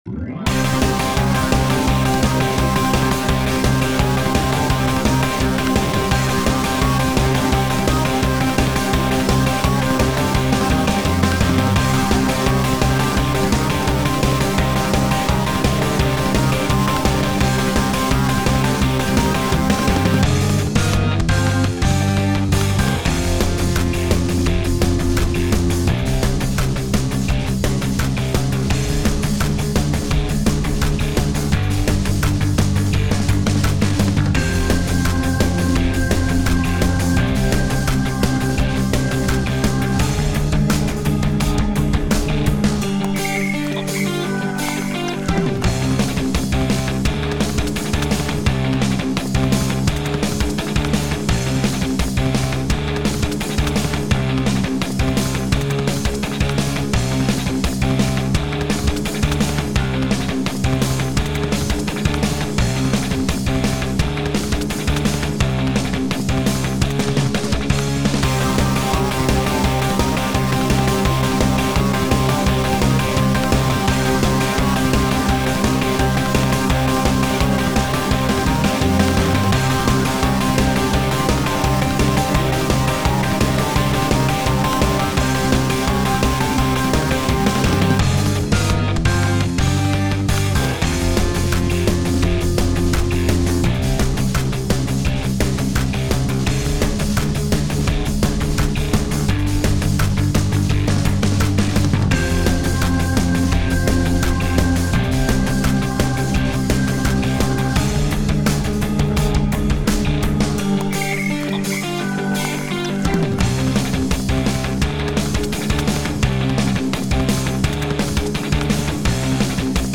エレキギターが唸る熱い戦闘曲
ロック 3:25 ダウンロード